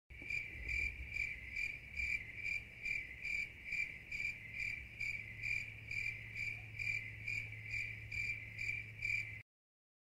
Efek suara Awkward
Kategori: Suara meme
Keterangan: Tambahkan efek suara awkward silence/ sound effect Crickets pas suasana tiba-tiba jadi canggung.
efek-suara-awkward-id-www_tiengdong_com.mp3